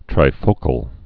(trī-fōkəl, trīfō-)